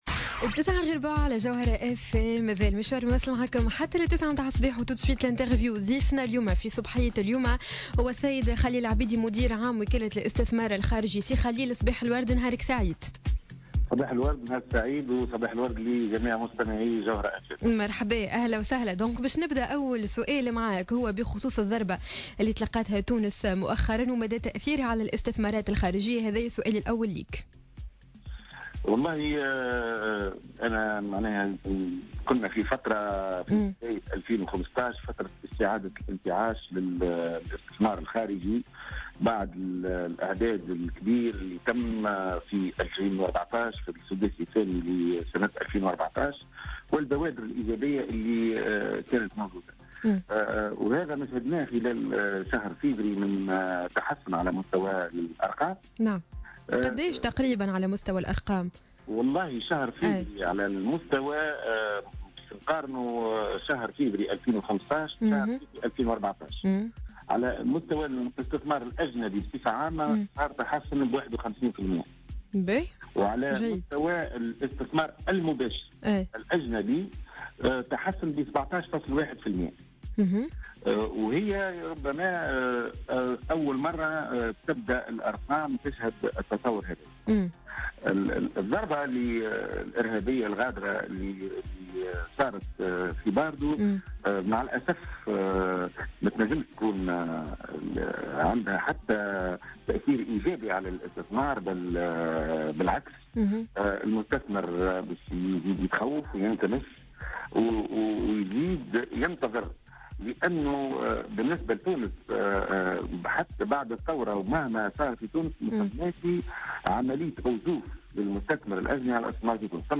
أكد مدير عام وكالة الاستثمار الخارجي خليل العبيدي في مداخلة له على جوهرة اف ام...